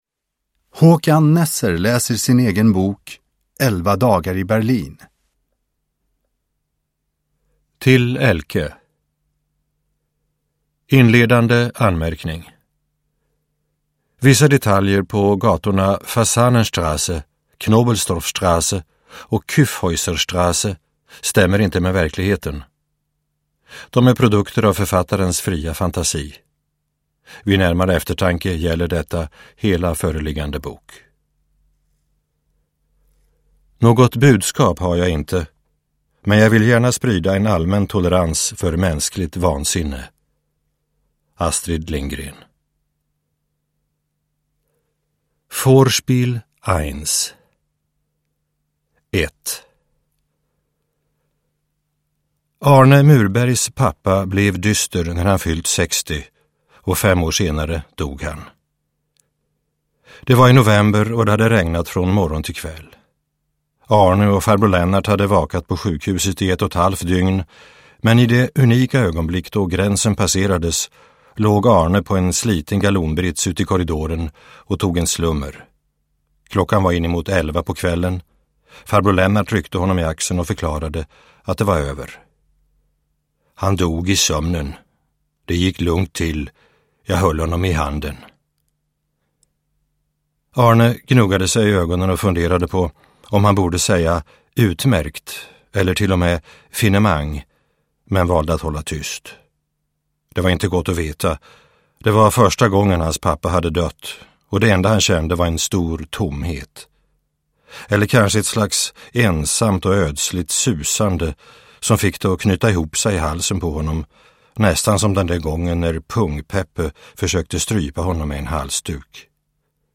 Uppläsare: Håkan Nesser
Ljudbok